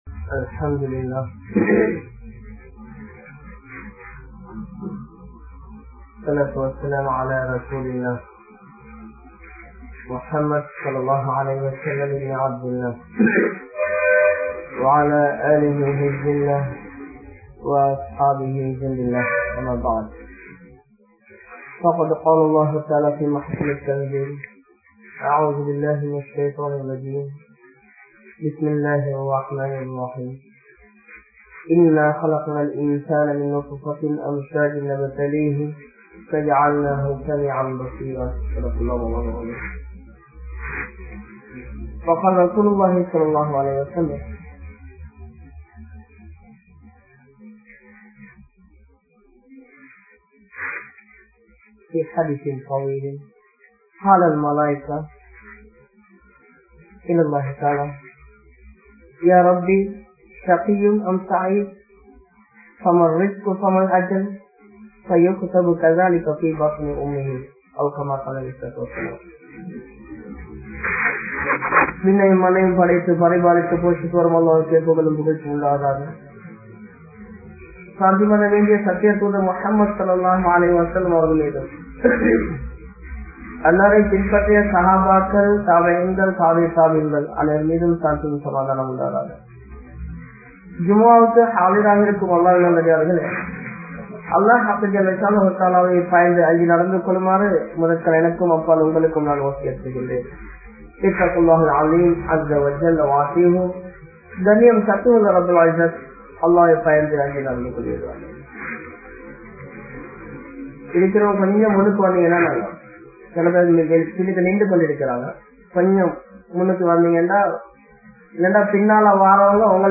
Nearaththai Veenadikkum Manitharhal (நேரத்தை வீனடிக்கும் மனிதர்கள்) | Audio Bayans | All Ceylon Muslim Youth Community | Addalaichenai
Saliheen Jumua Masjidh